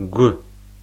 ɡʷ audio speaker icon
гу Gwen